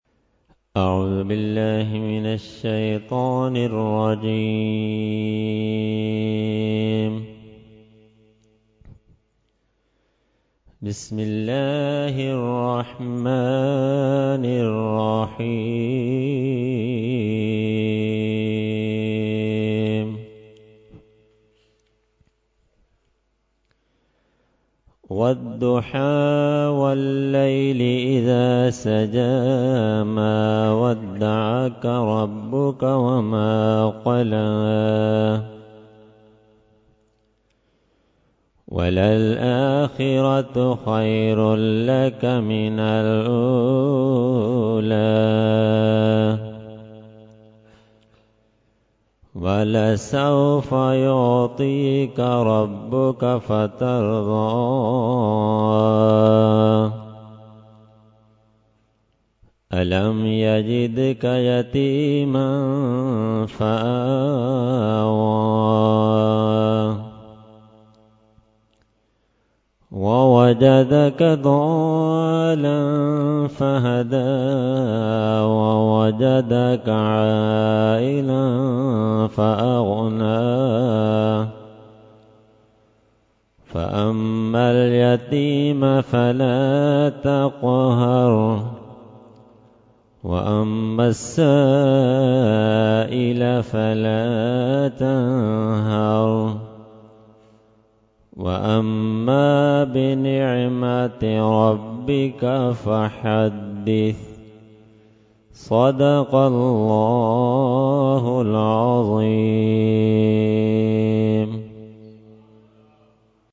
Qirat – 11veen Shareef 2017 – Dargah Alia Ashrafia Karachi Pakistan